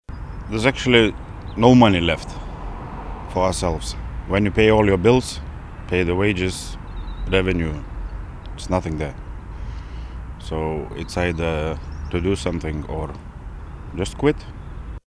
This truck driver at Ashbourne in Co. Meath says rising prices are devastating: